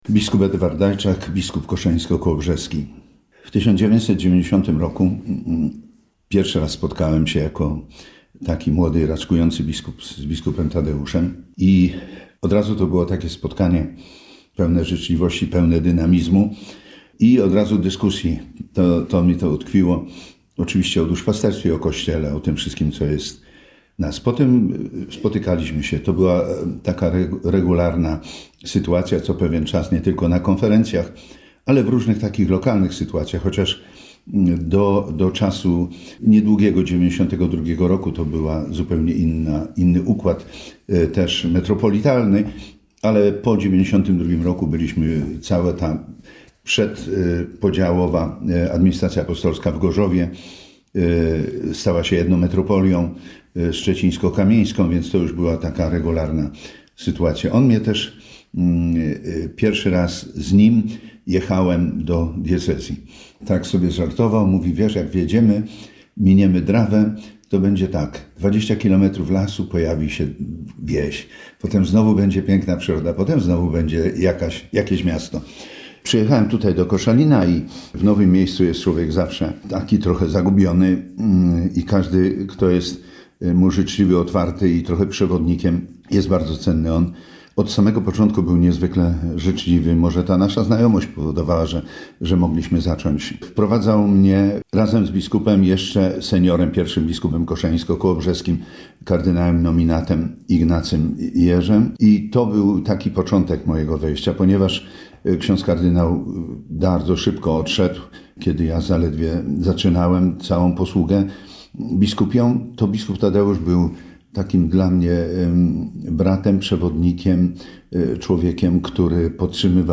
Bp Dajczak o bp Werno - wypowiedź.mp3